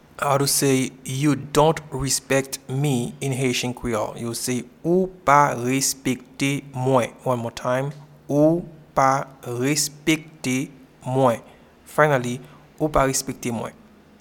Pronunciation and Transcript:
You-dont-respect-me-in-Haitian-Creole-Ou-pa-respekte-mwen.mp3